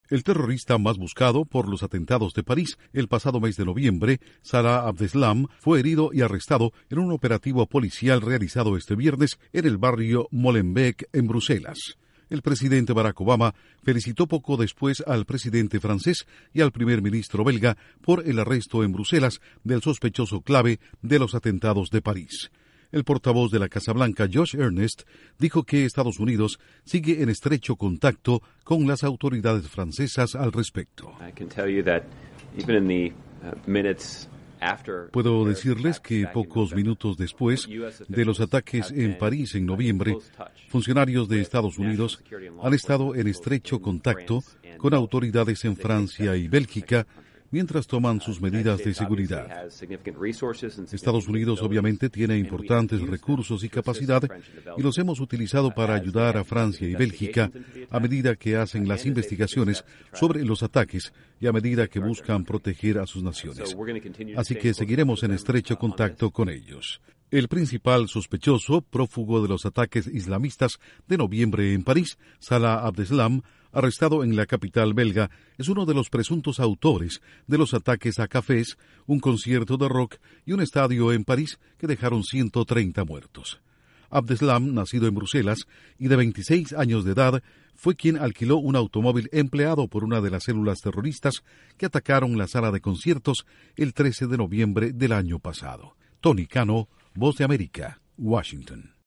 Casa Blanca: Estados Unidos sigue trabajando con Francia y Bélgica en la lucha contra el terrorismo. Informa desde la Voz de América